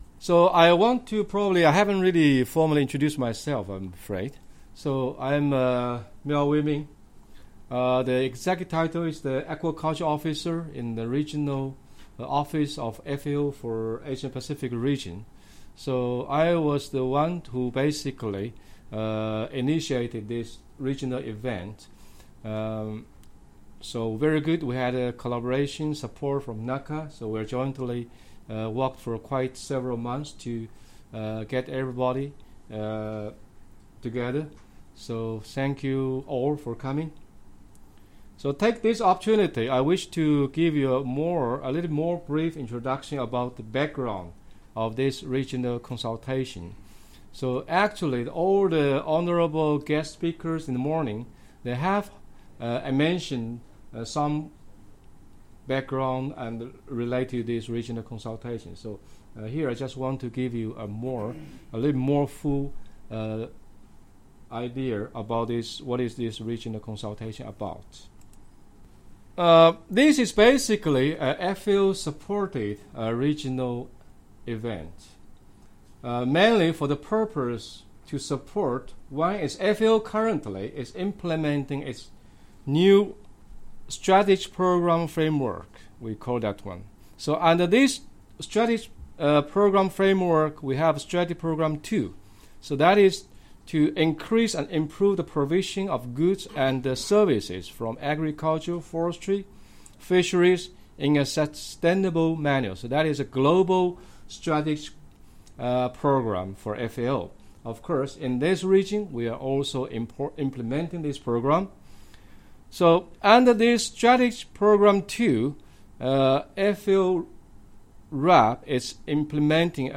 The consultation reviewed the current situation of aquaculture feed production and use, sourcing of ingredients, policy and research needs. This collection contains audio recordings of the technical presentations made by experts, international organisations, the private sector and governments in the region.